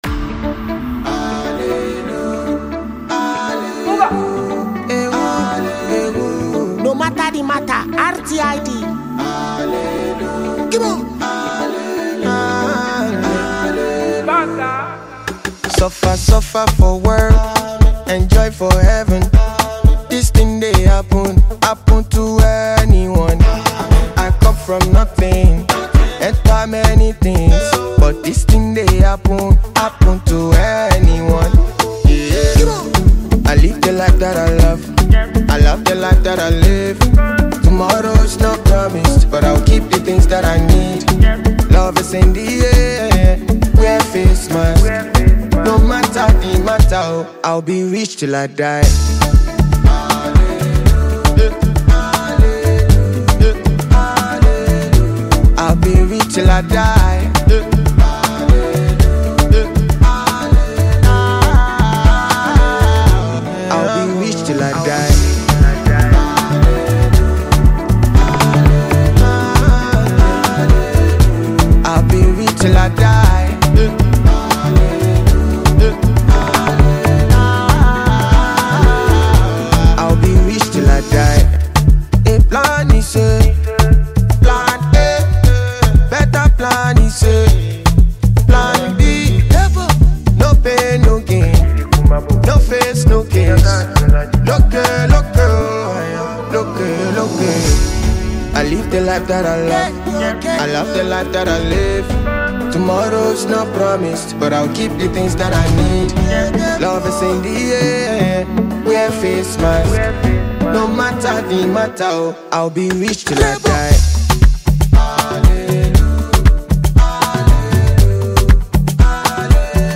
Nigerian Afroclassic singer and songwriter